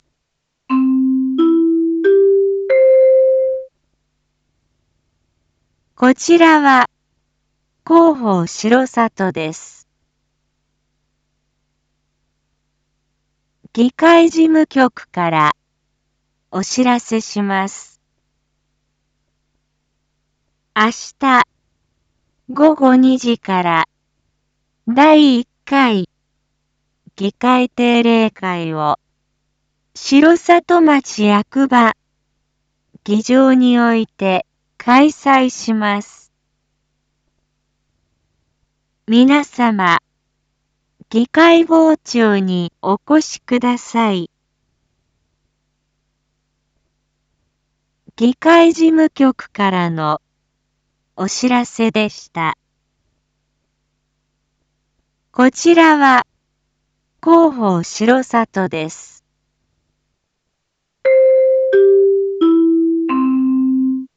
一般放送情報
BO-SAI navi Back Home 一般放送情報 音声放送 再生 一般放送情報 登録日時：2024-03-14 19:01:05 タイトル：第１回議会定例会⑦ インフォメーション：こちらは広報しろさとです。